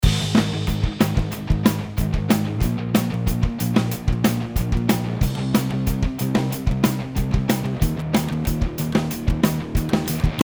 • アンサンブルに入ると
やり過ぎ感は否めませんが、アタックの音がわかりやすくなりますね。